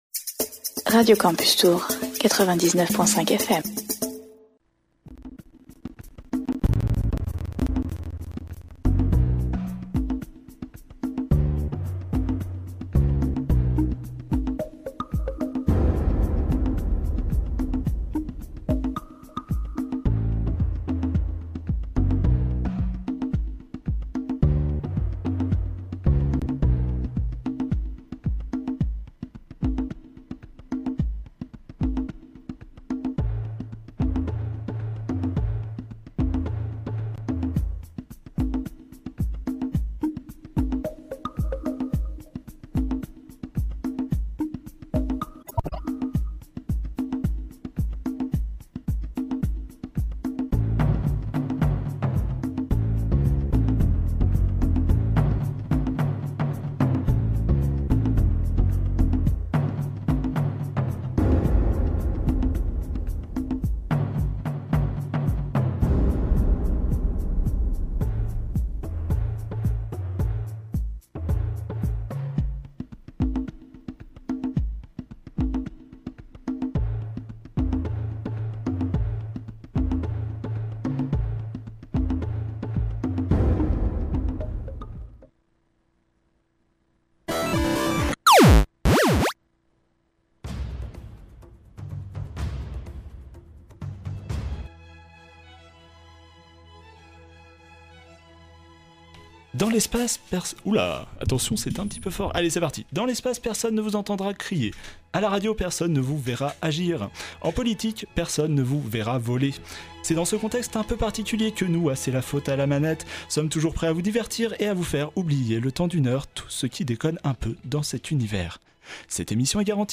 Quelques bugs dans les musiques